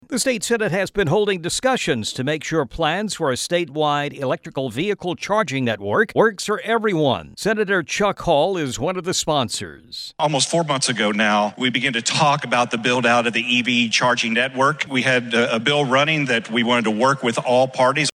featuring comments from Chuck Hall.